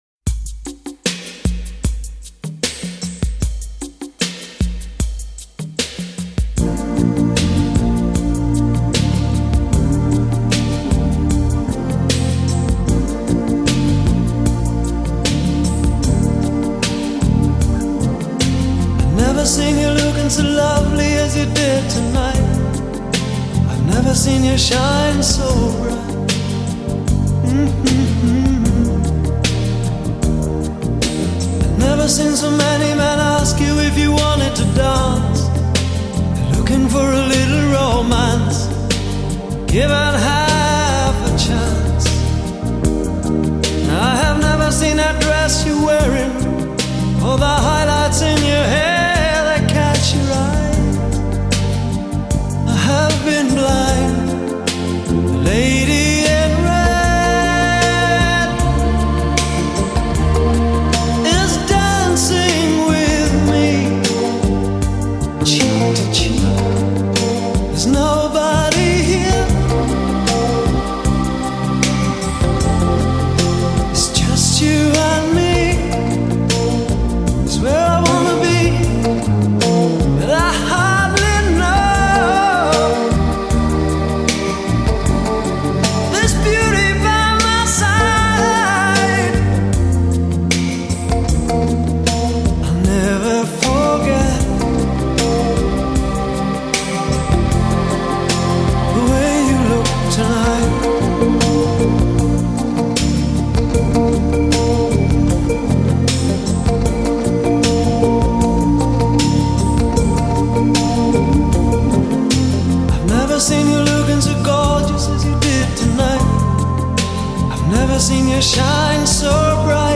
唯有白金級的情歌才能夠成就為經典